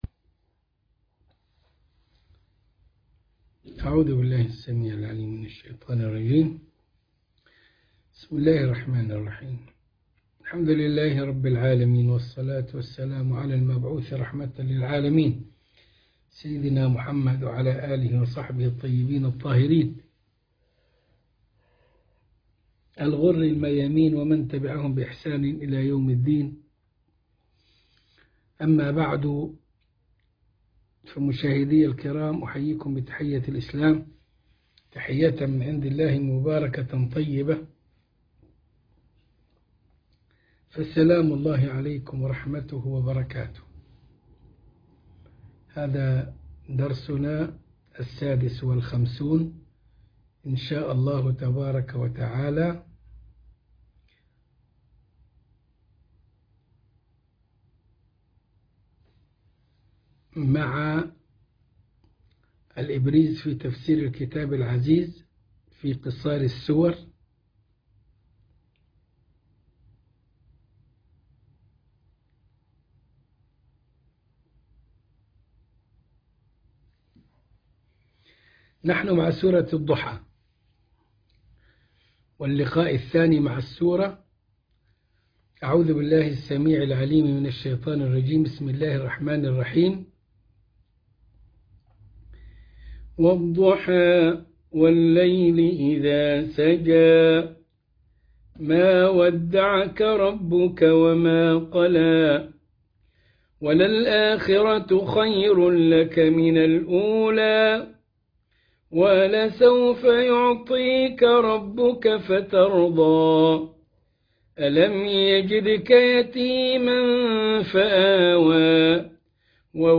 الدرس ٥٦ من قصار السور سورة الضحى